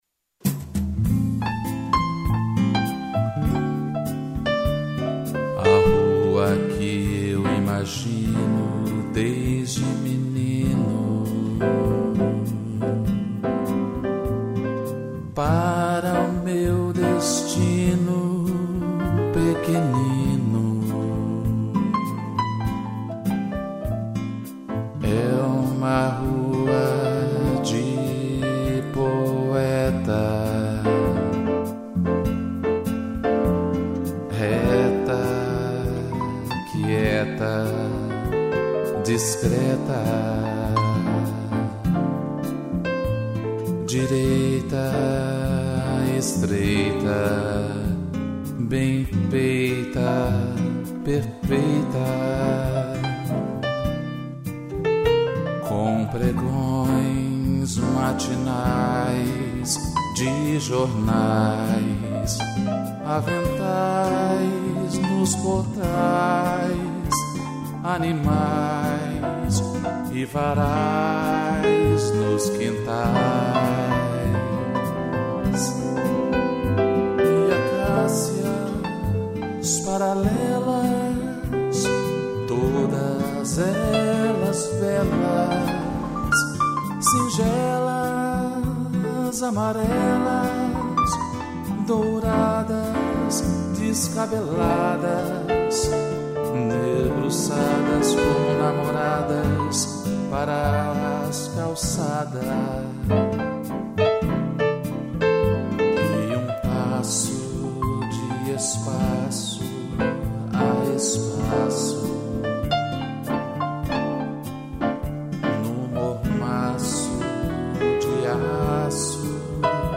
voz e violão